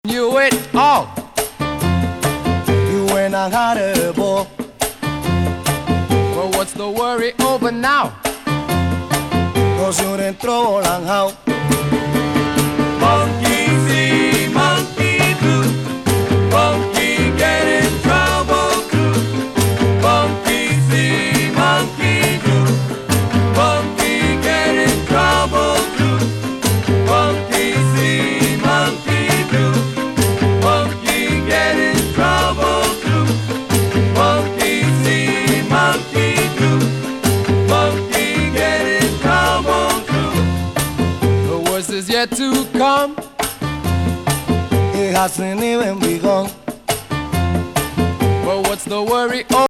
ヴァイブがグッときます。KILLER BOOGALOO大名盤!